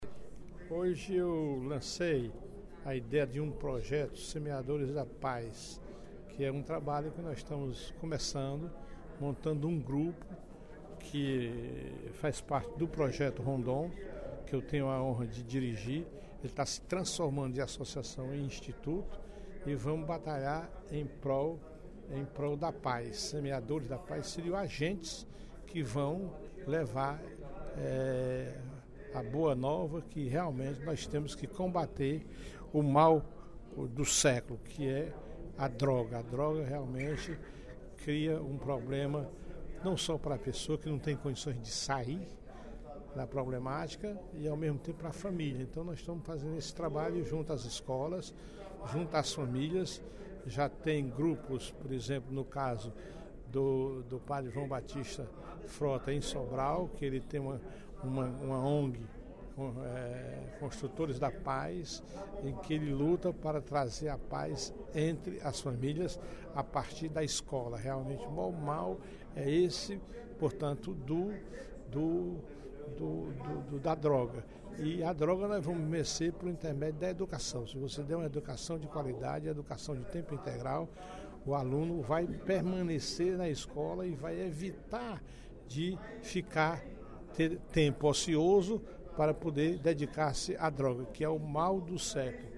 Durante o primeiro expediente da sessão plenária desta terça-feira (20/05), o deputado Professor Teodoro (PSD) destacou o projeto de indicação de sua autoria que propõe a criação do programa Semeadores da Paz.